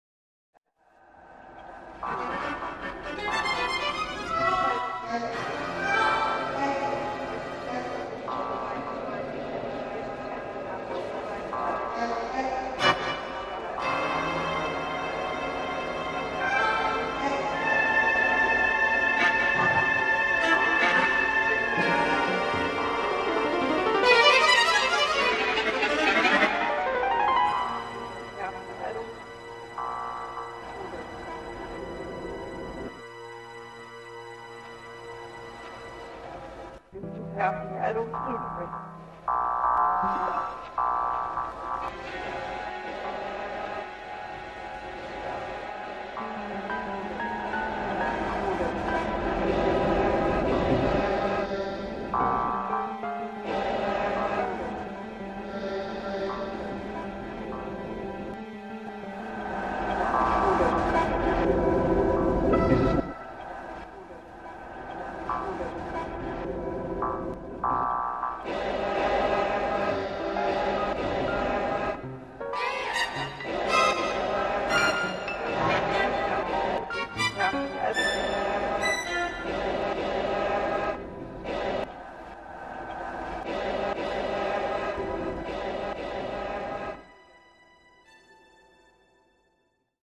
Elektronische Musik
"Sprache".  für Singstimme, Vl,Vcl, E-Bass, 2 Pno im 1/4 Ton Abstand